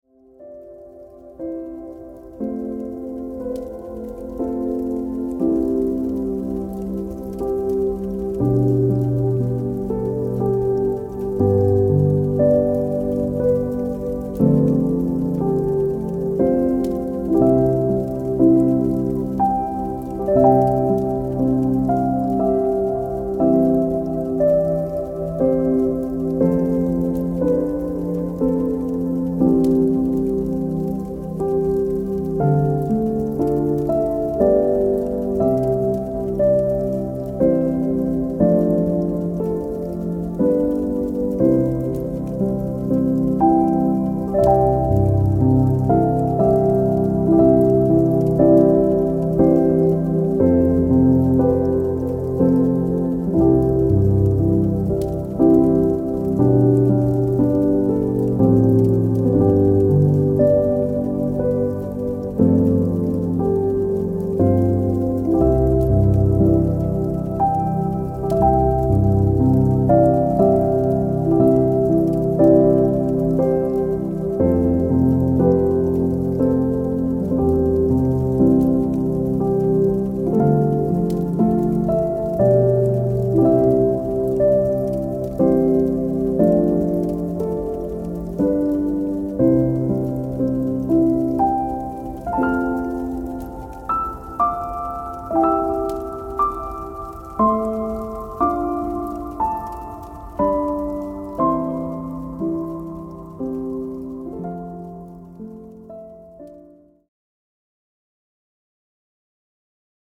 44.1 kHz / Stereo Sound